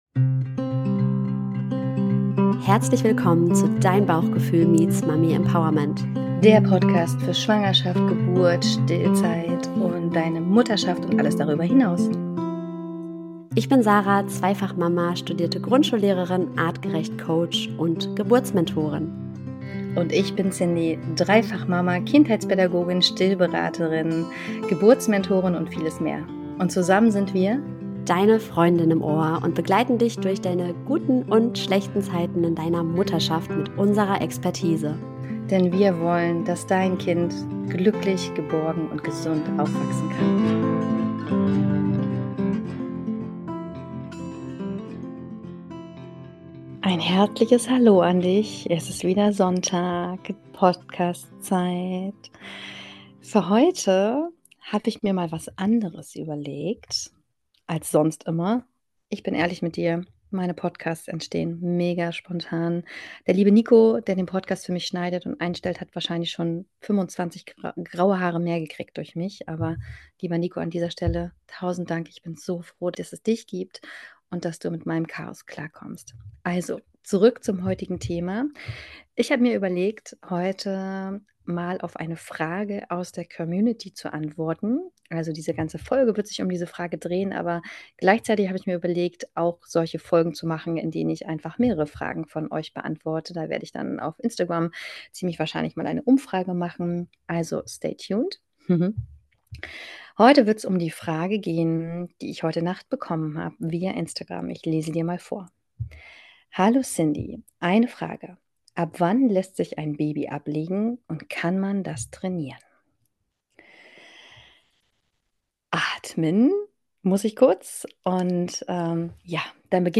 eine tiefgehende, bewegende und ehrliche Solo-Folge rund um ein Thema, das viele Eltern betrifft